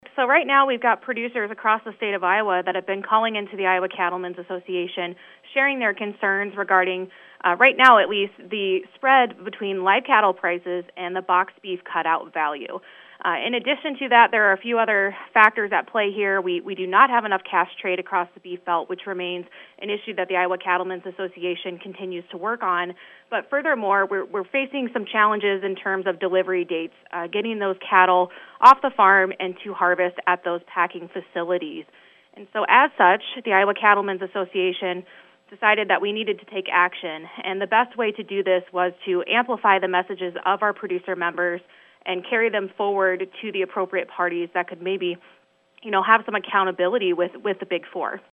Audio: Full interview